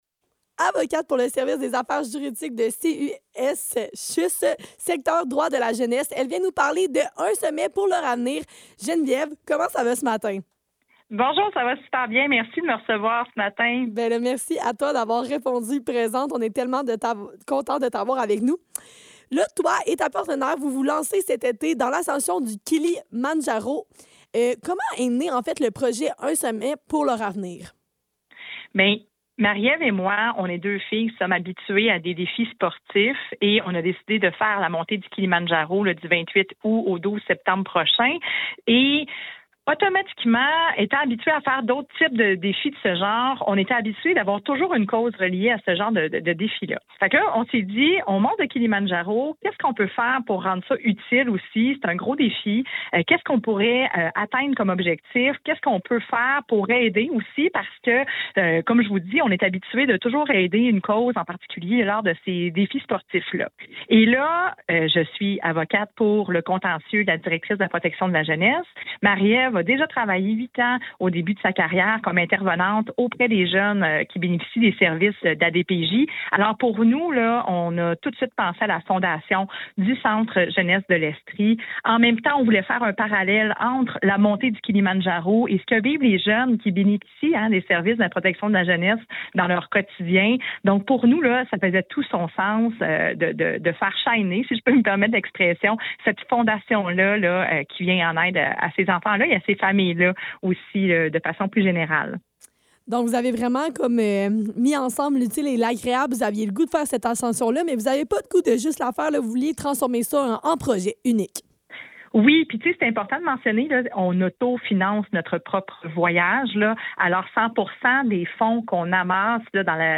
Le Neuf - Entrevue dans le cadre de : Un sommet pour leur avenir - 5 février 2026